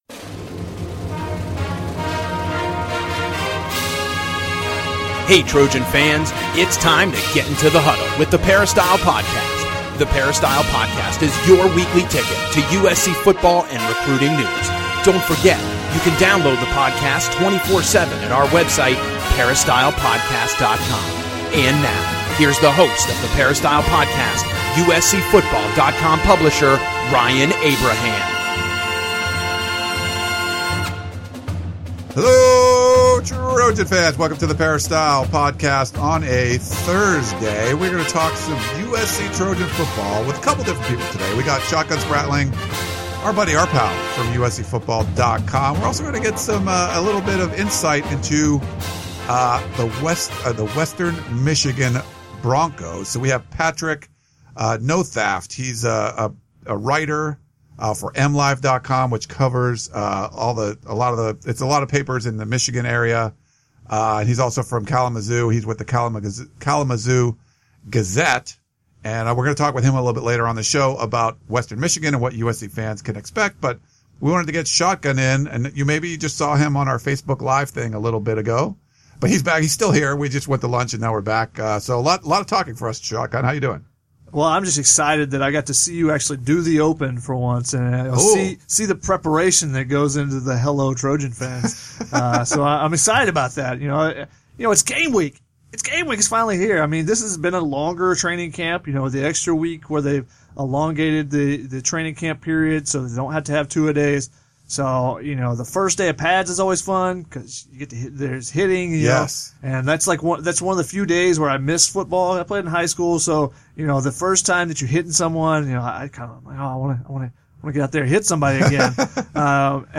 comes in studio to answer your Trojan football questions